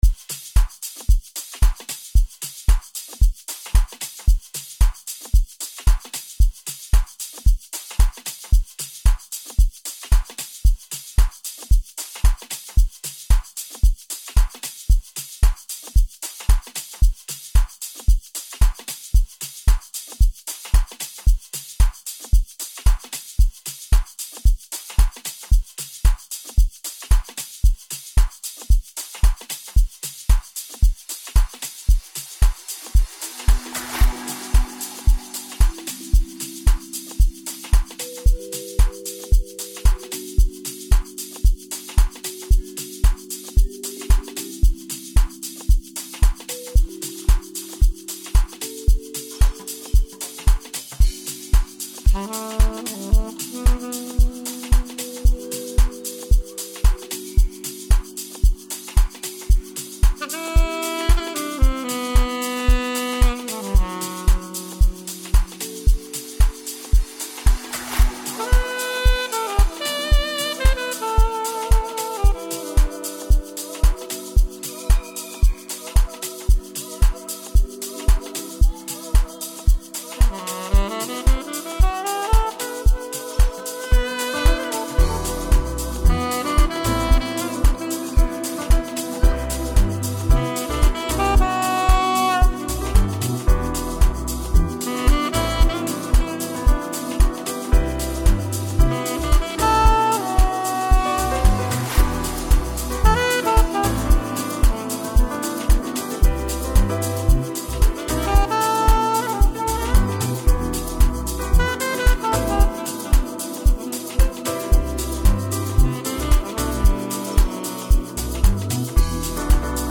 • Genre: Amapiano